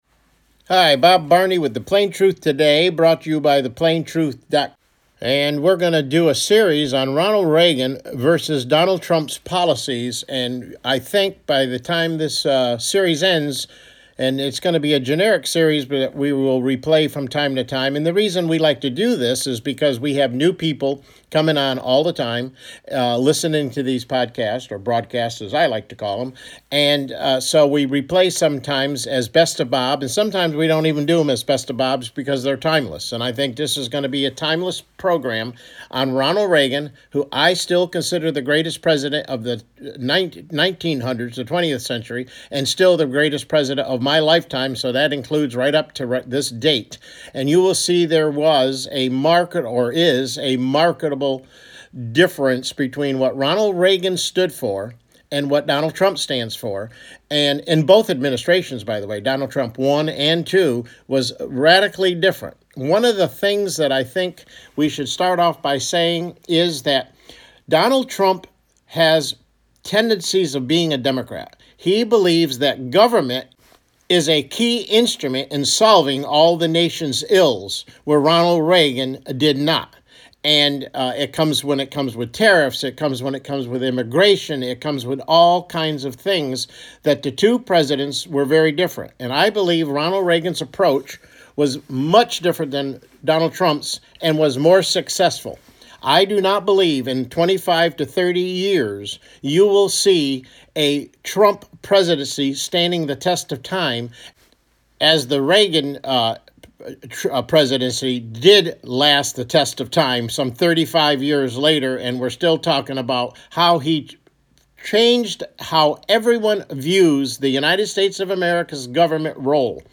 CLICK HERE TO LISTEN TO THE PLAIN TRUTH TODAY MIDDAY BROADCAST: Ronald Reagan vs Donald Trump Part 1